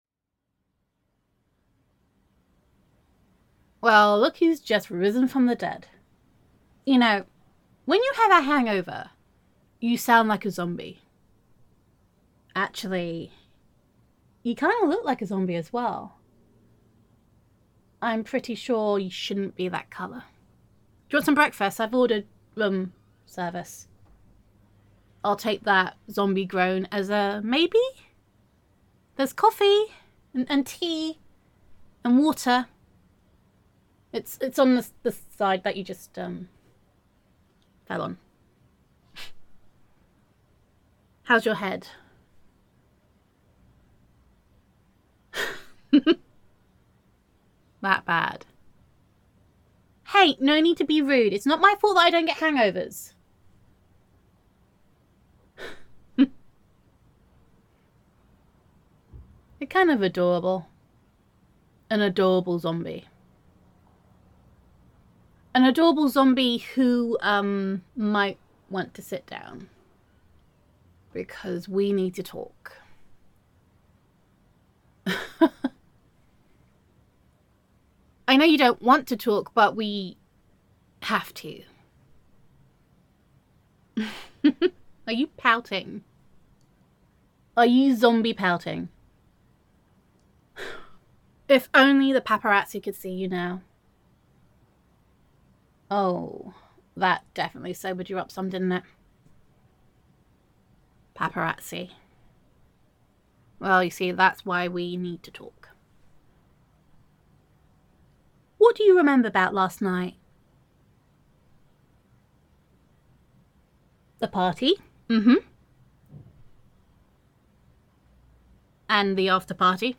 [F4A] What Happens in Vegas